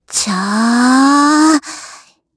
Gremory-Vox_Casting2_kr.wav